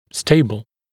[‘steɪbl][‘стэйбл]стабильный, устойчивый